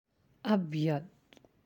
(abyad)